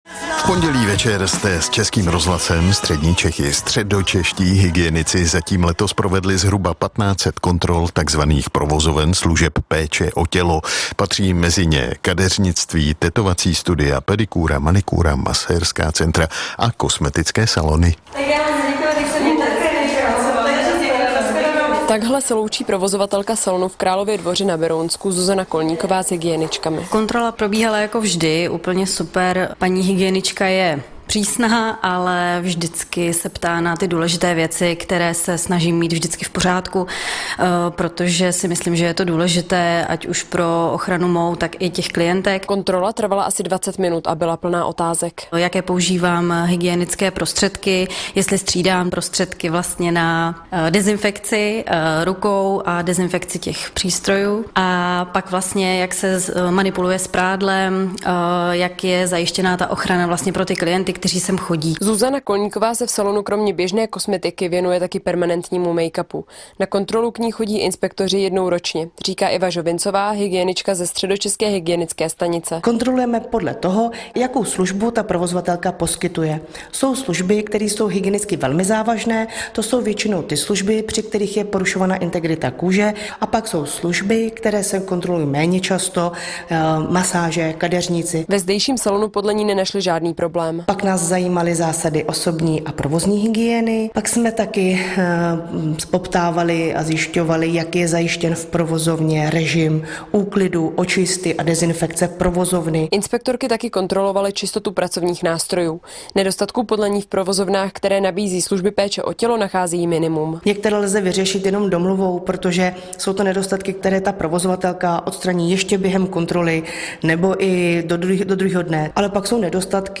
Na kontrolu kosmetického salonu V Králově Dvoře vyrazil s našimi hygieničkami i Český rozhlas.